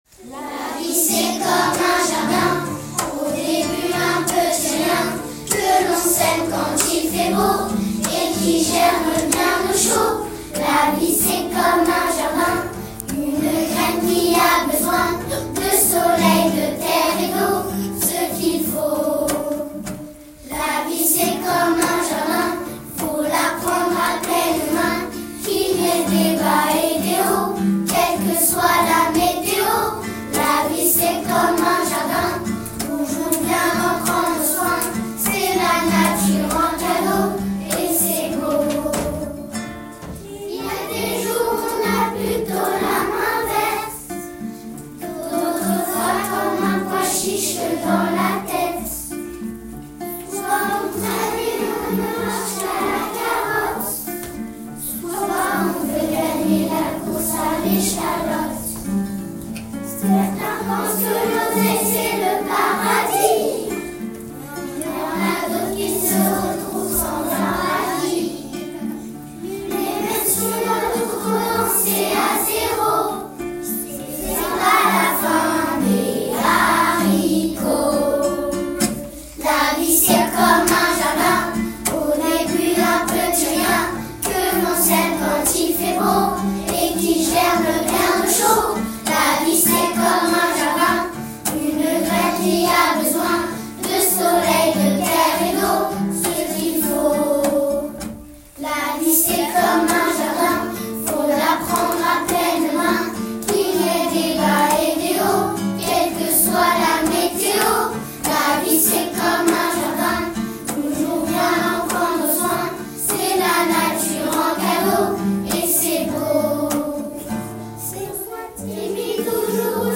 Partager la musique: Les CP et les CE2/CM1 chantent ensemble.